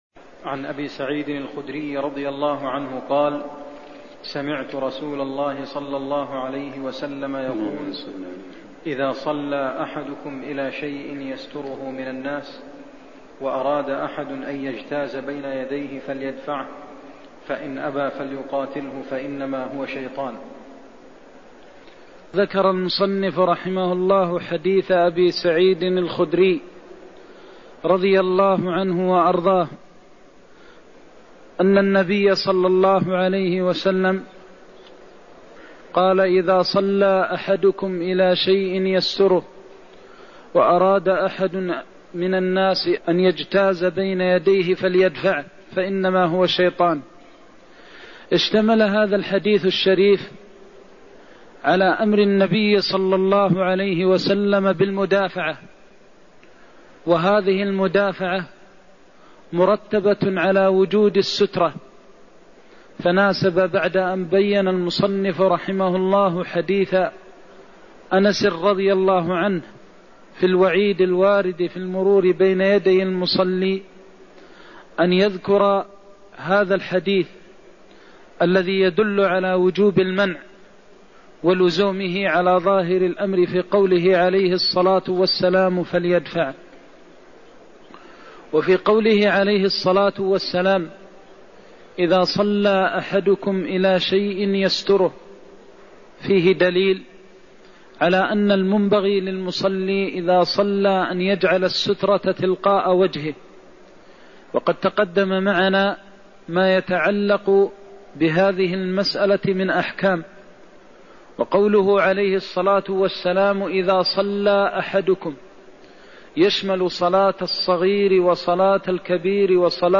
المكان: المسجد النبوي الشيخ: فضيلة الشيخ د. محمد بن محمد المختار فضيلة الشيخ د. محمد بن محمد المختار أمر النبي بالمدافعة بعد وضع السترة (103)